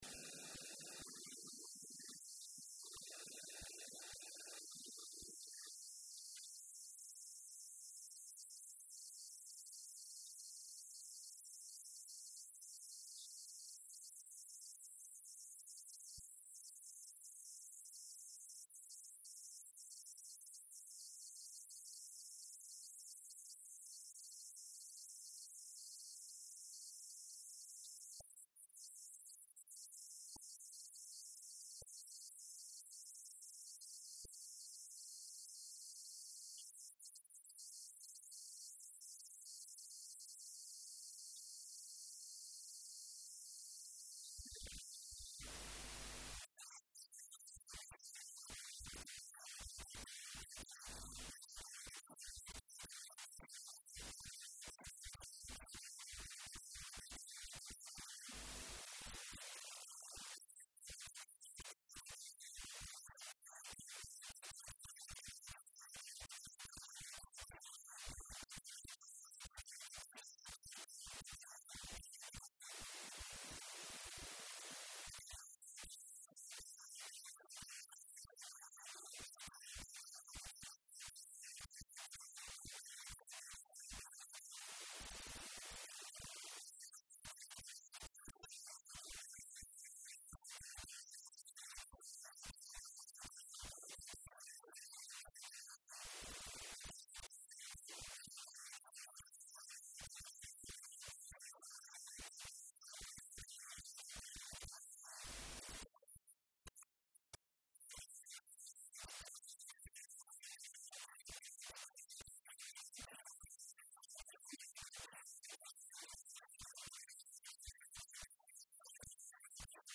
Dhageyso Warka Habeen ee Radio Muqdisho